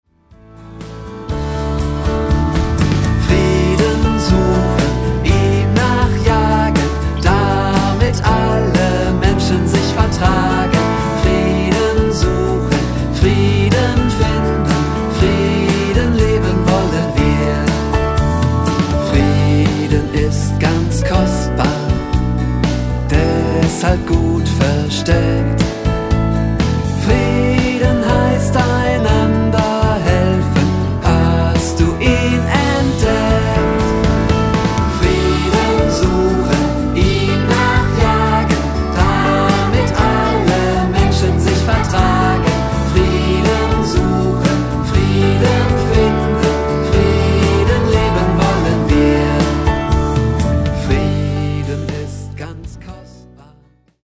. Eingängige Melodien und einfache Texte.